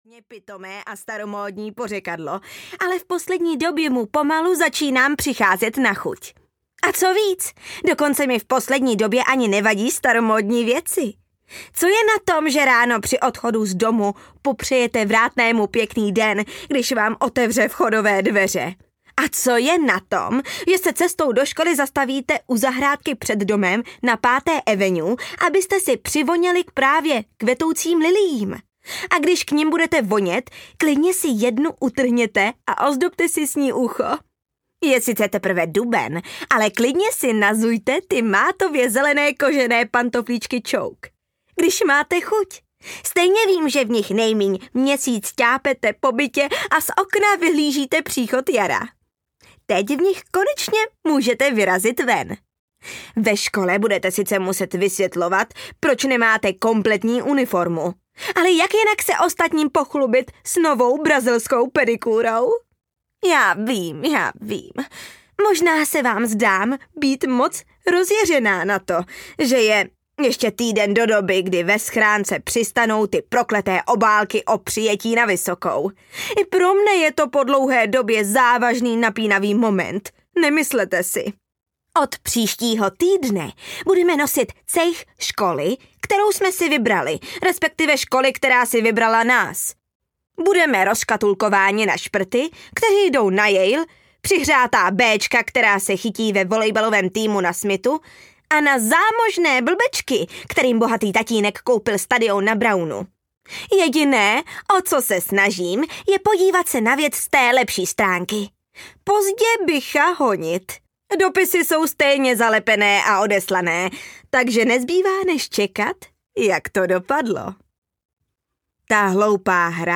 Gossip Girl: Chci jedině tebe (6. díl) audiokniha
Ukázka z knihy